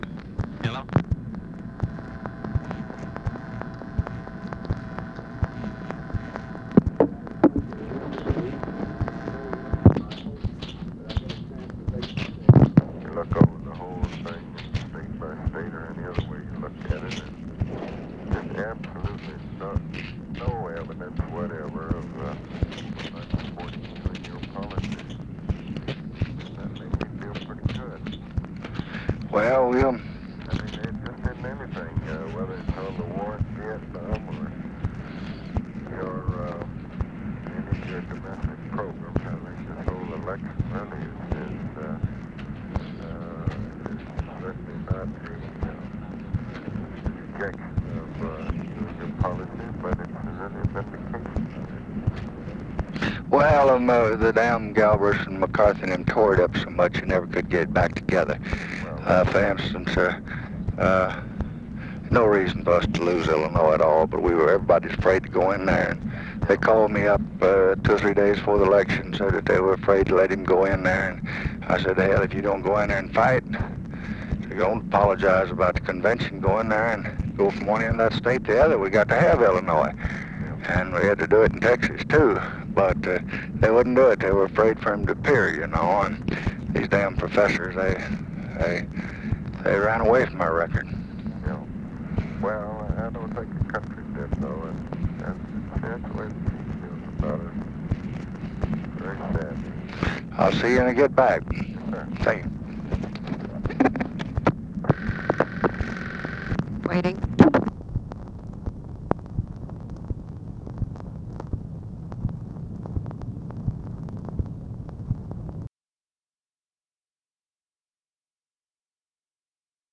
Conversation with ABE FORTAS, UNIDENTIFIED MALE and OFFICE NOISE, November 6, 1968
Secret White House Tapes